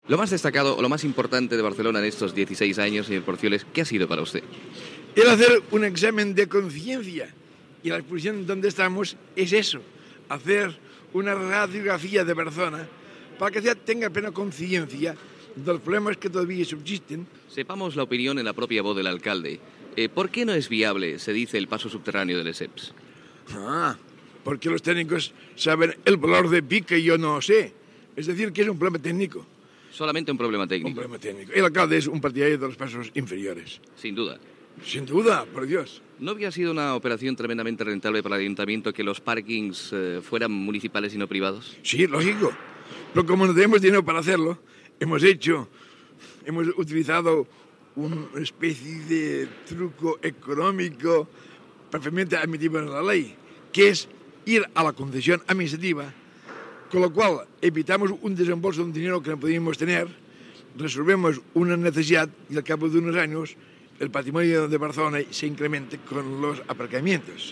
Entrevista a l'alcalde de Barcelona José María de Porcioles que opina sobre el pas soterrat de la Plaça Lesseps i la privatització dels aparcaments municipals de Barcelona
Informatiu